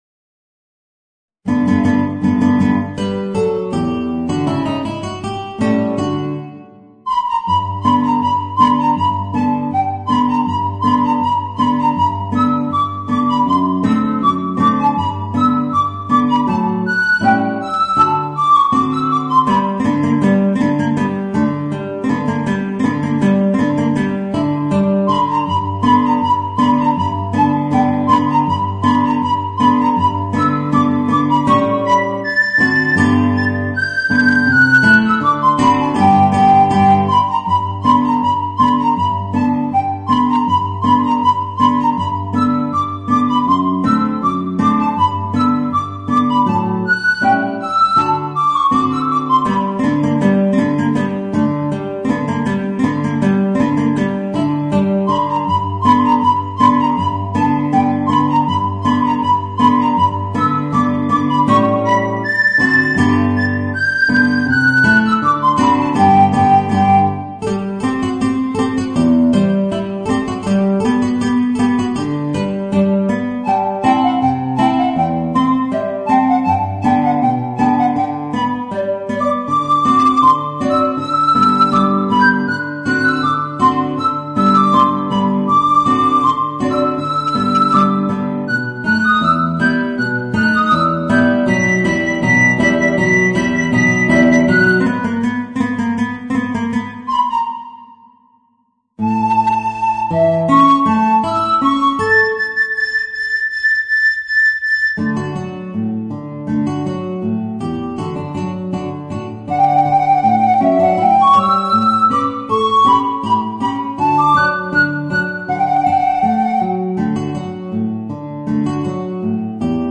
Voicing: Guitar and Soprano Recorder